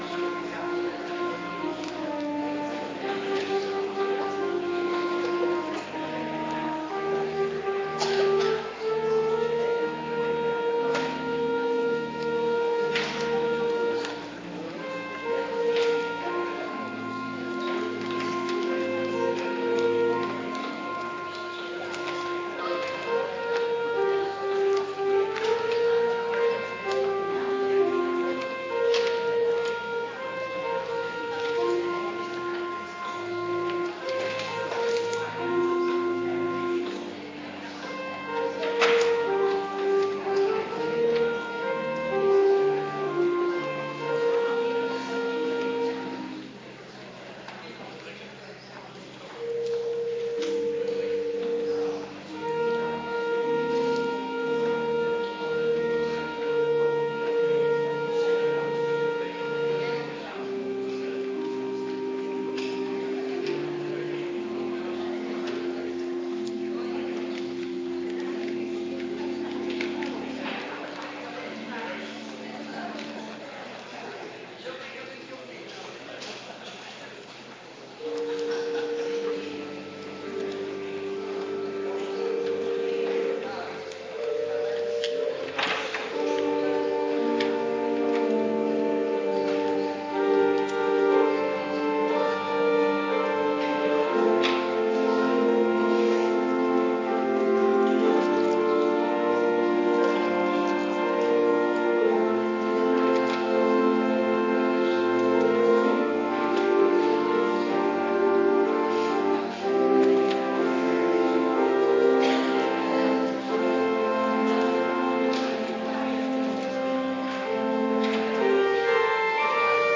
Kerkdiensten